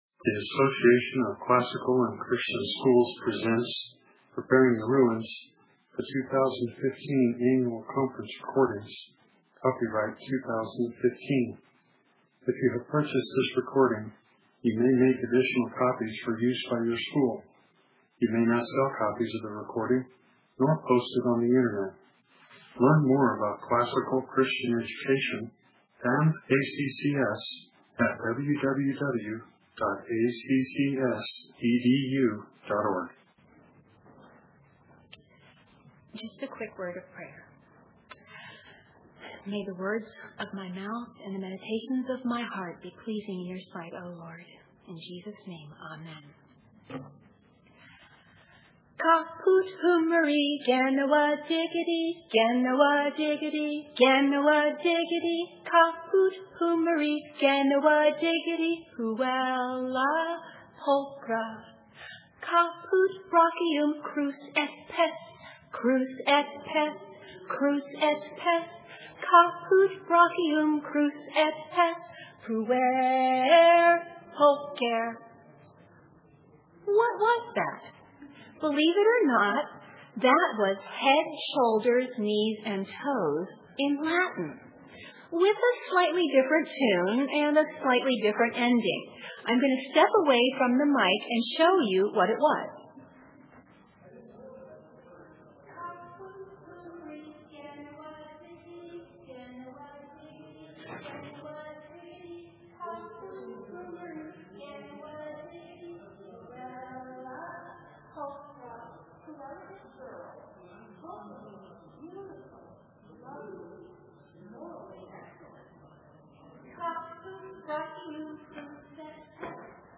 2015 Workshop Talk, 0:48:33, K-6, Latin, Greek & Language